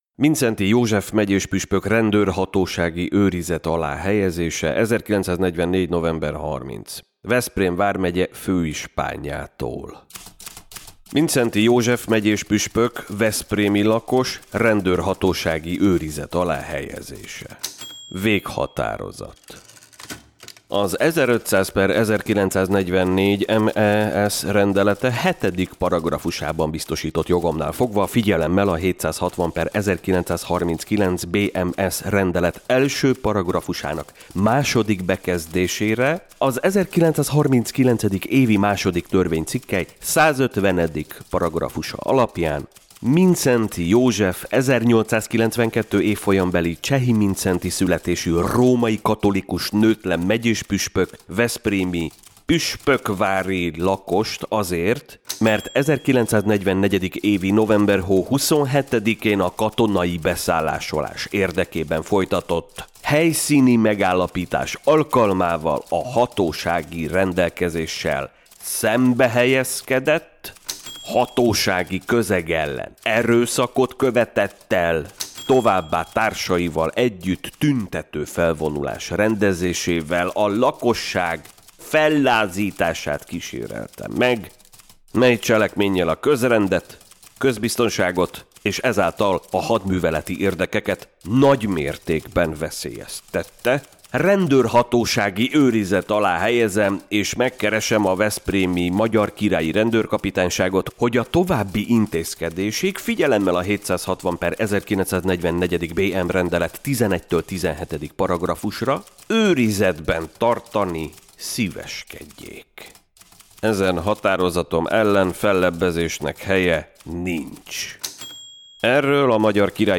Schiberna-veghatarozata_irogeppel.mp3